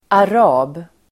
Uttal: [ar'a:b]